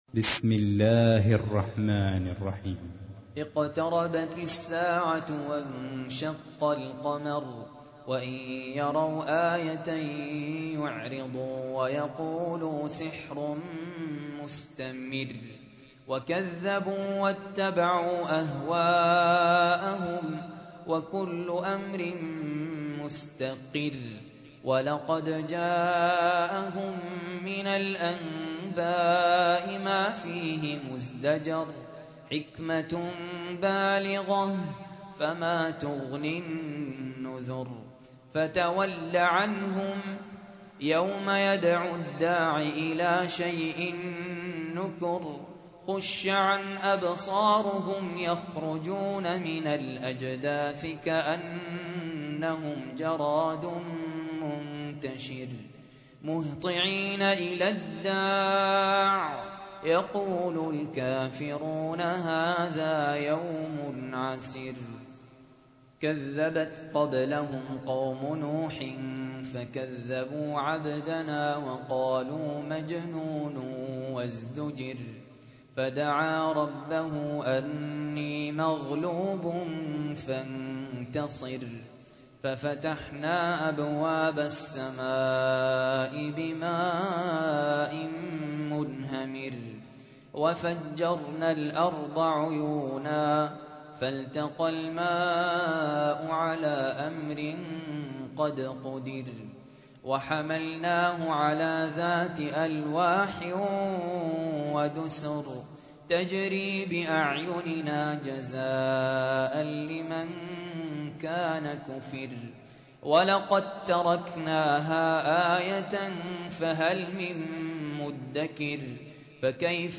تحميل : 54. سورة القمر / القارئ سهل ياسين / القرآن الكريم / موقع يا حسين